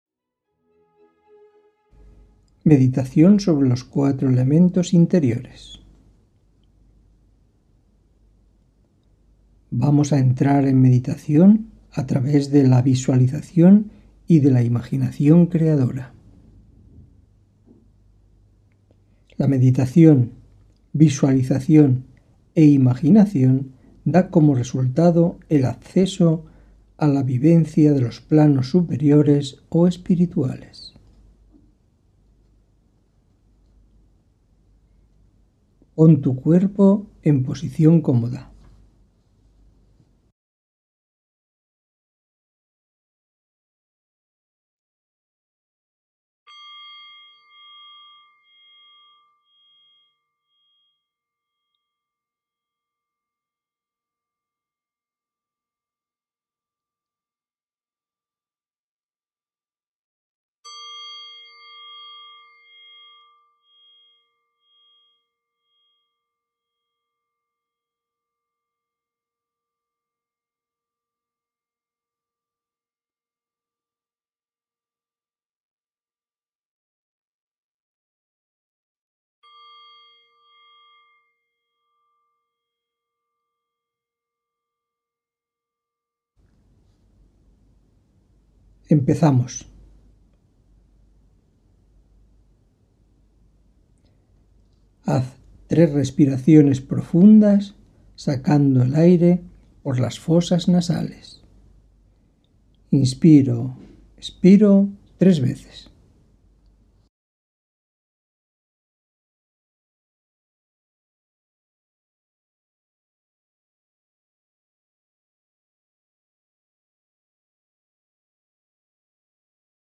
En la Meditación Guiada hay que mantener la Atención en la Voz del Instructor, manteniendo la concentración en la visualización de las imágenes que el Instructor propone a cada instante.
El gong marca el inicio y el final de la Meditación. Hoy vamos a realizar la Meditación sobre: Los Cuatro Elementos, más un Quinto Elemento.
Meditación-Guiada-Cuatro-Elementos.mp3